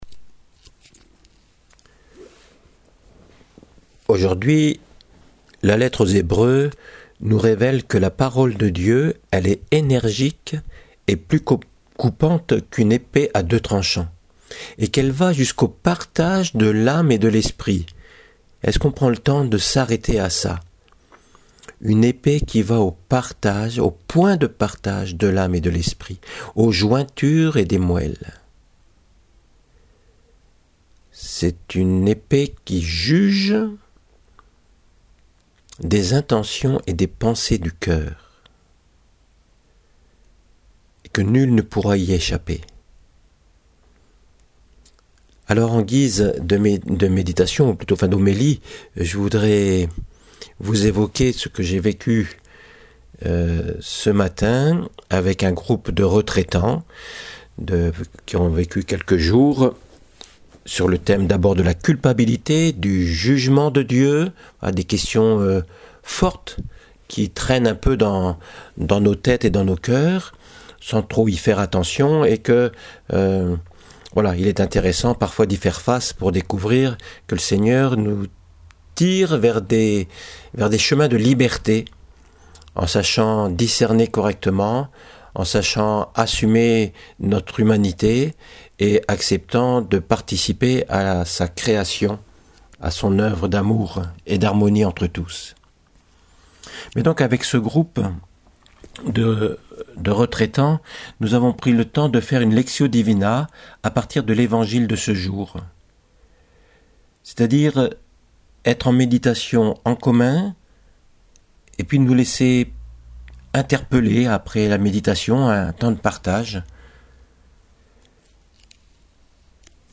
homélie dominicale: la vie éternelle en héritage
une première en version audio évoquant une lectio divina avec un groupe de retraitant ici au Berceau c’est juste ci-dessous: